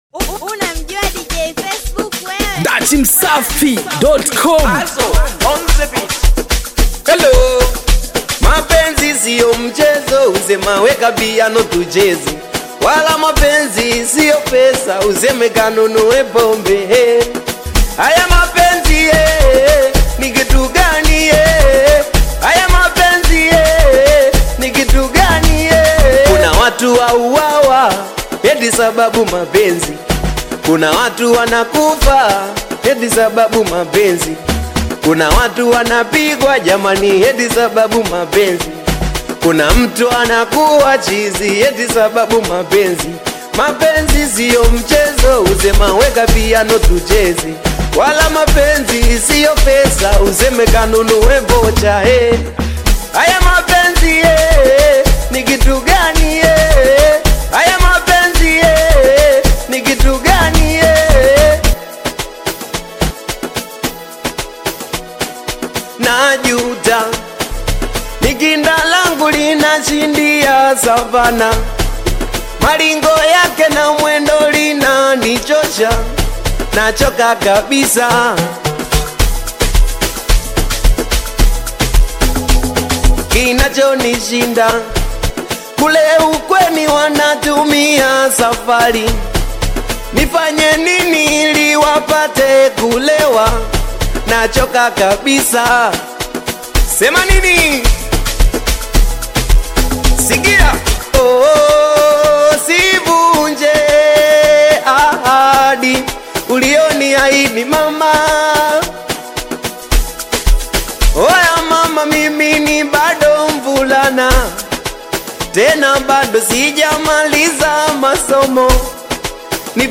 Dj Mix / Beat